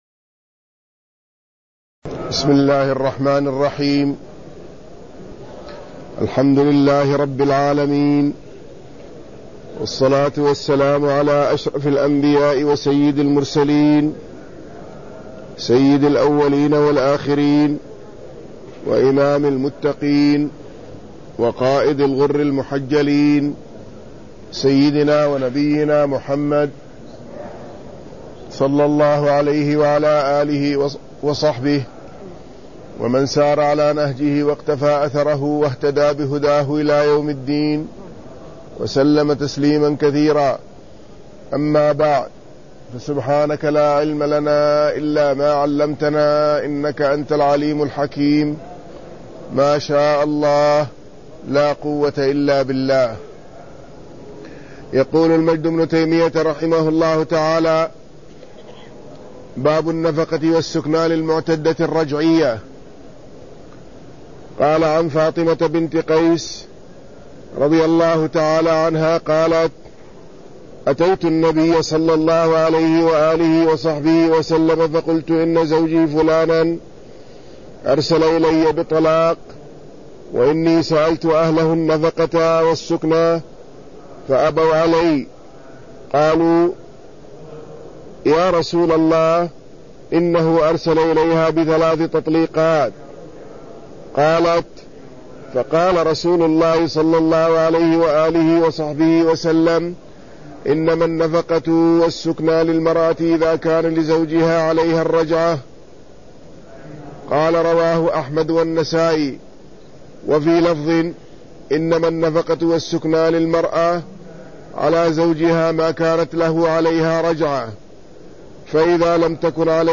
المكان: المسجد النبوي الشيخ